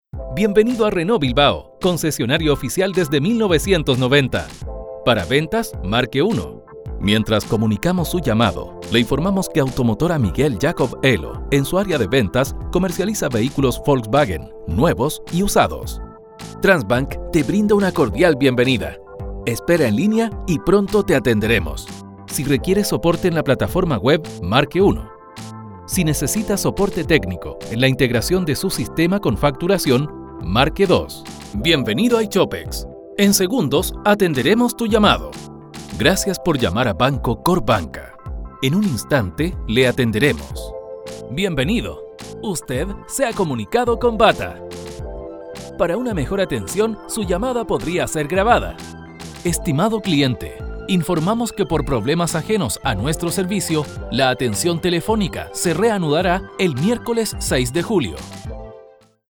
Voz Central Telefónica IVR
Voz interactiva, cercana y creíble, orientando al cliente de forma agradable para comunicarse al departamento correspondiente.
• Sala Acústica para grabaciones limpias de ruidos.
demo-mix-locucionivr.mp3